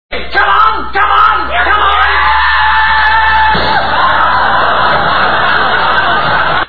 - A chicken like scream performed by Graham Chapman on many occasions.
chickenscream2.wav